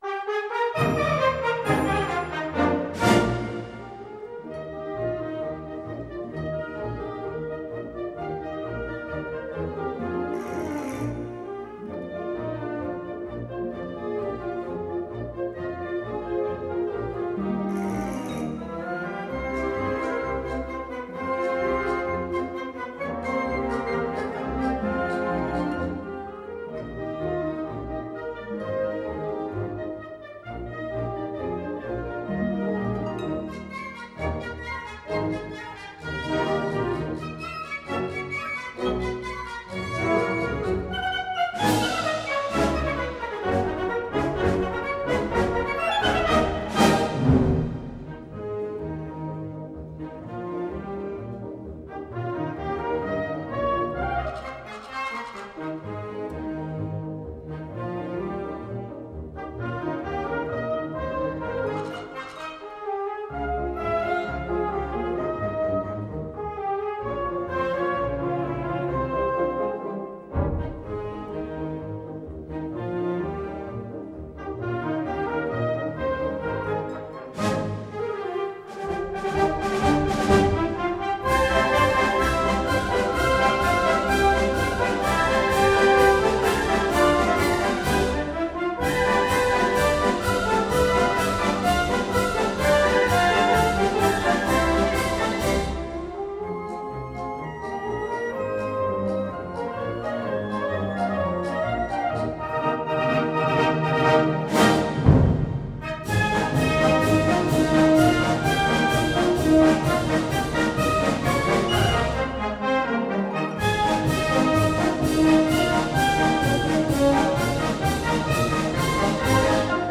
Pasodoble [coreable]